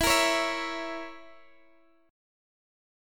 A#dim/E chord